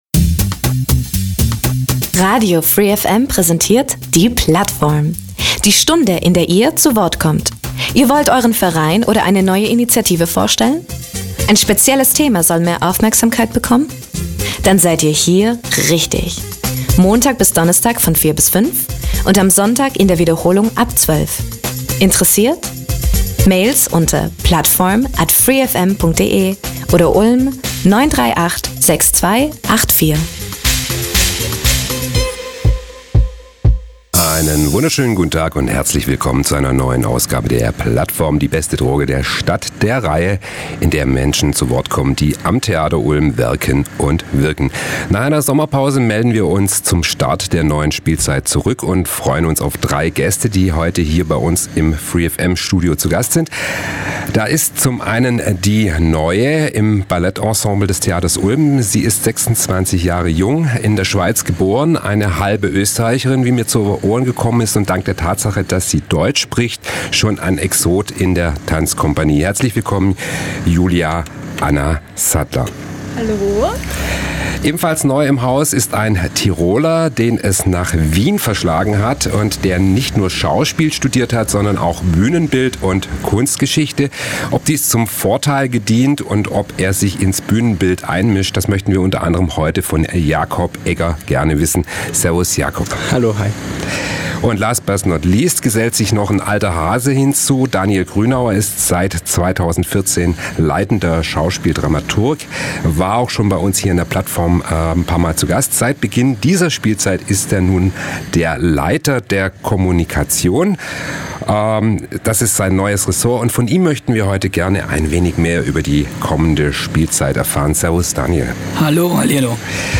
Die Sendung Plattform vom 12.09.2016 um 16 Uhr: Die Sommerpause ist vorüber und zur ersten Sendung der Spielzeit werden gleich drei Gäste im Studio sein.